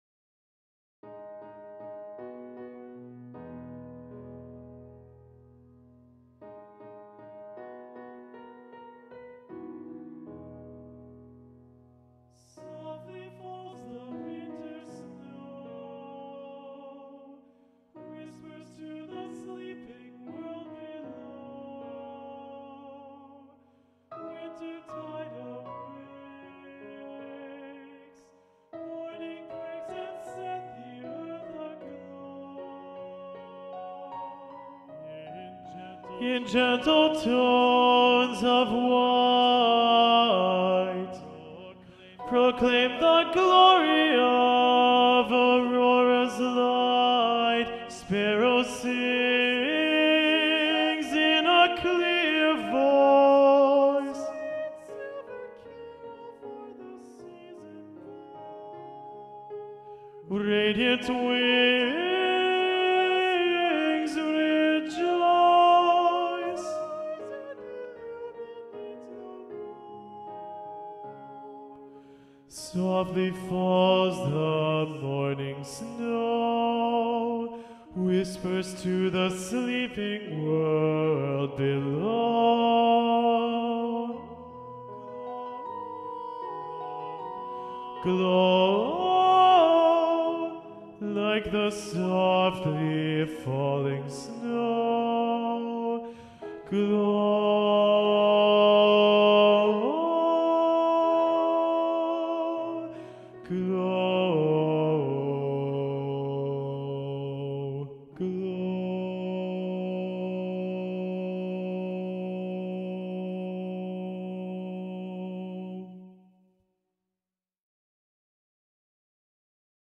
- Chant normalement à 4 voix mixtes SATB + piano
SATB Tenor Predominant